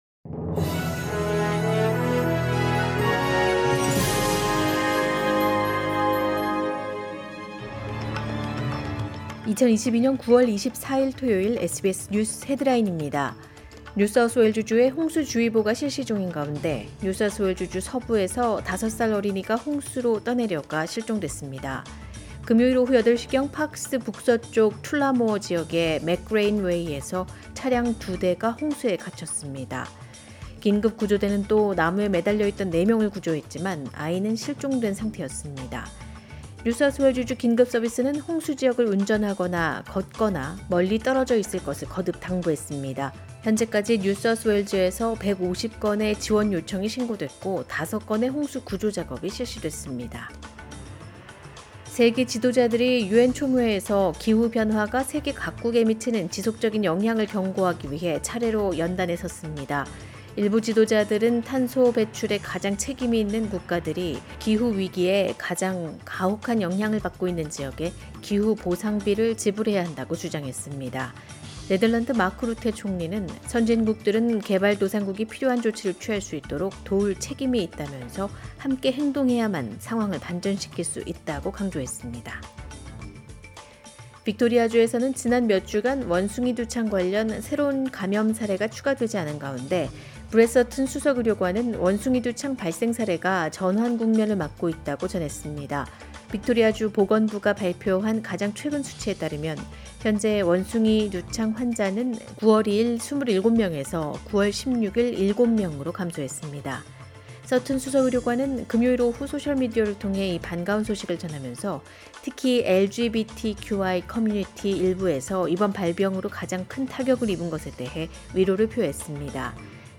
2022년 9월 24일 토요일 SBS 한국어 간추린 주요 뉴스입니다.